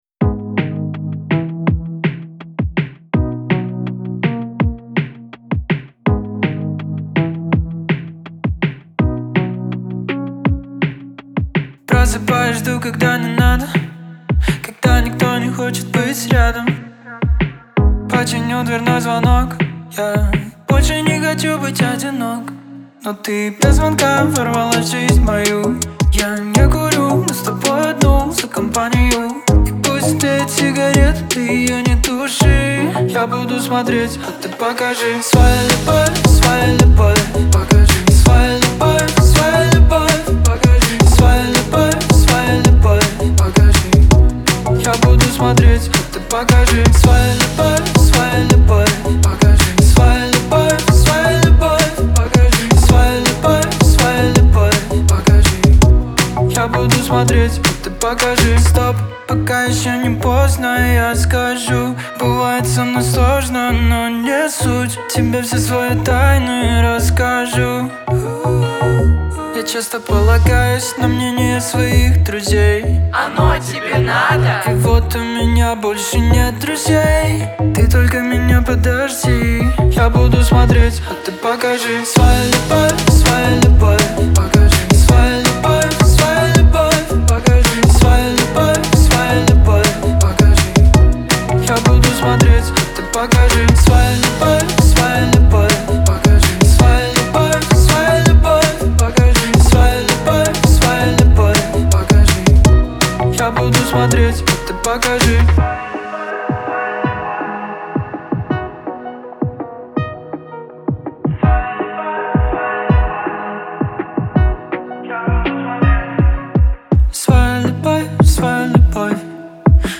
зажигательная поп-песня